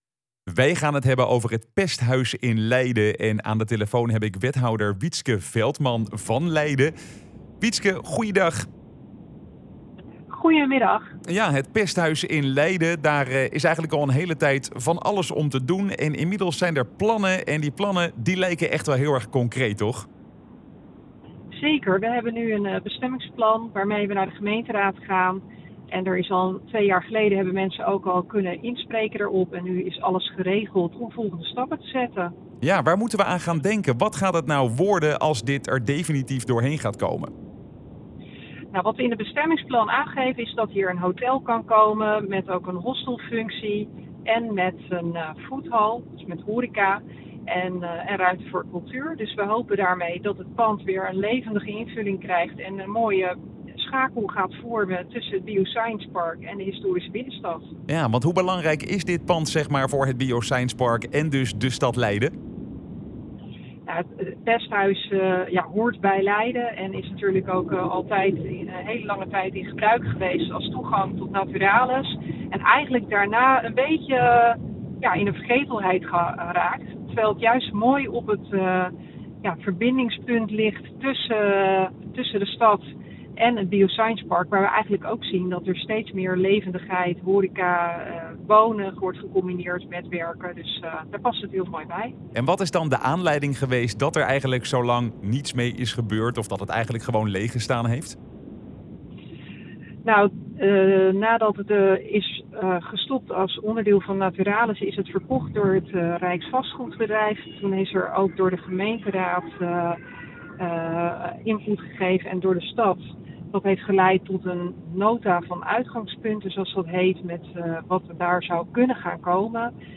in gesprek met wethouder Wietske Veltman over het Pesthuis.